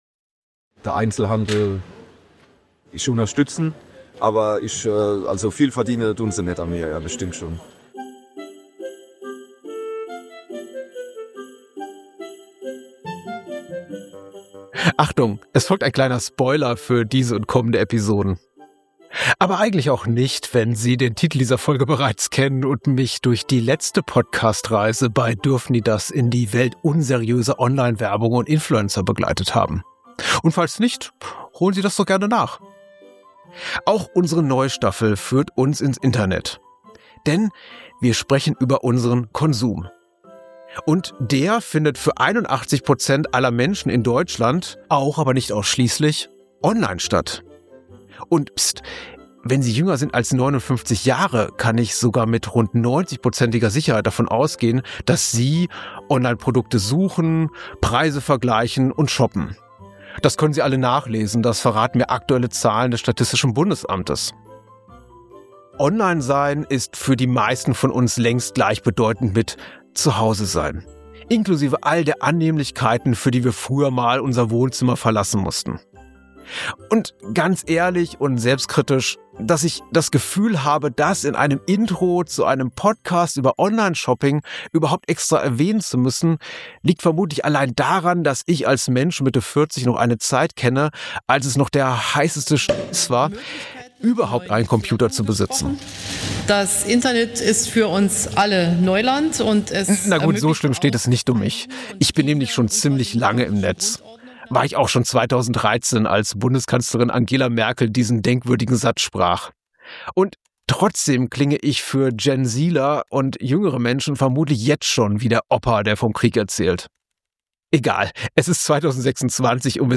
In Expertengesprächen setzen wir uns mit den Dauerbrennern bzw. Dauerproblemen für Kundinnen und Kundinnen beim Onlineshopping auseinander. Wo stehen wir vor ungelösten Herausforderungen, wo ist Besserung in Aussicht, und mit welchen neuen Tricks wollen uns die Anbieter zum Geldausgeben verführen?